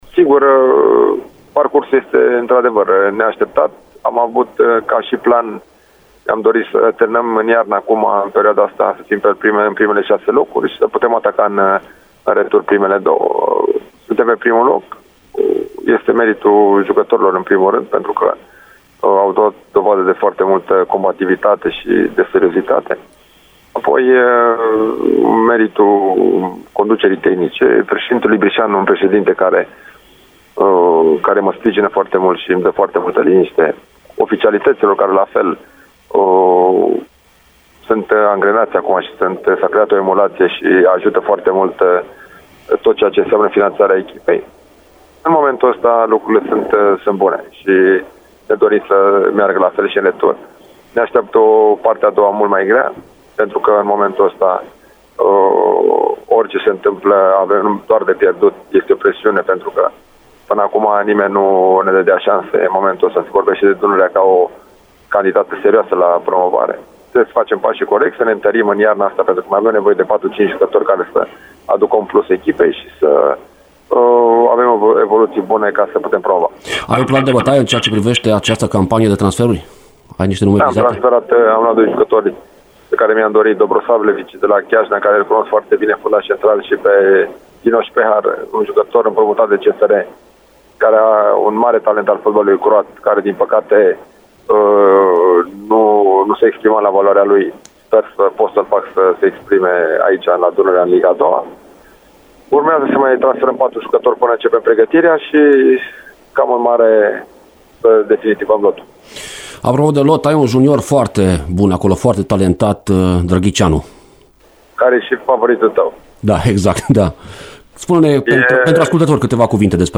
alături puteți asculta un fragment al dialogului, iar în emisiunea ce începe după știrile orei 11 va fi dialogul integral: